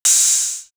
EDM Rinse High.wav